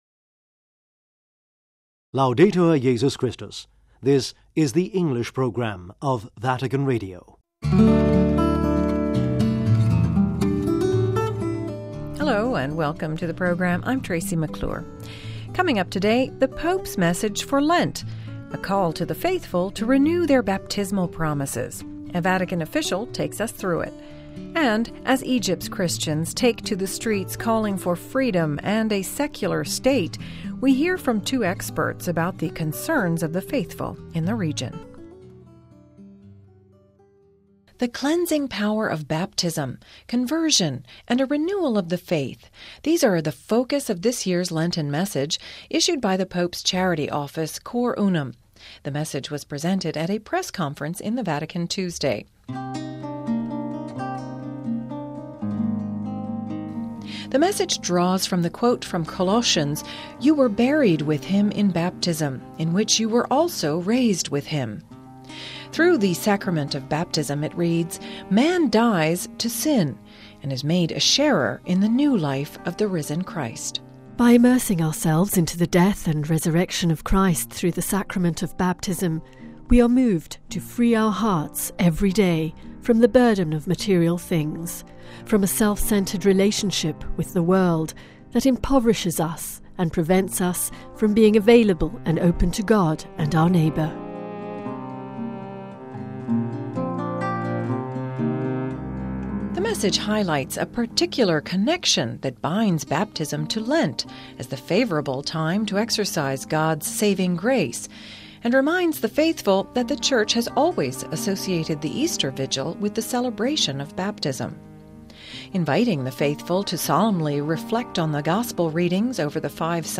The Pope’s Message for Lent - The Pope's charity office Cor Unum announces this year's message at a Vatican press conference. One Cor Unum official takes us through it. Christian Concerns - As Egypt’s Christians take to the streets calling for freedom and a secular state, we hear from two experts about the concerns of the faithful in the region...